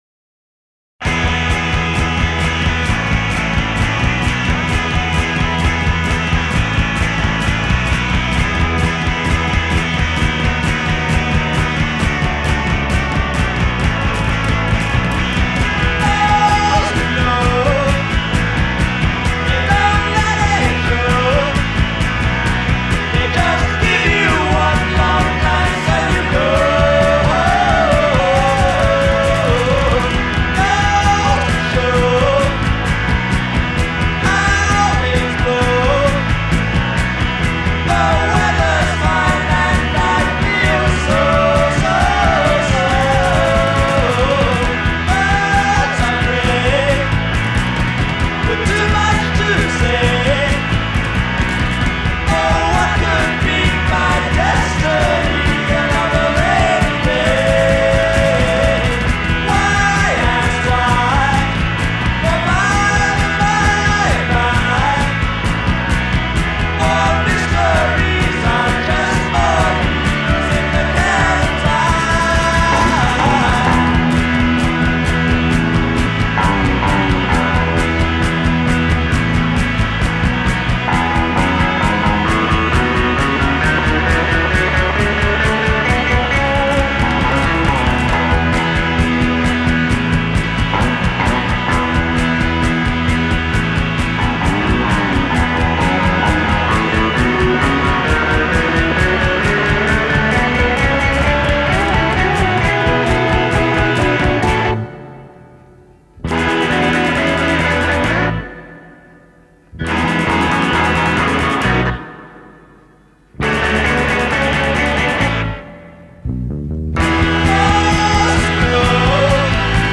art rock